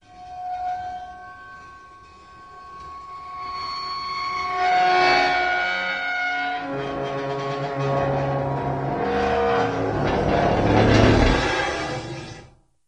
Metal Chalkboard Squeal Light to Hard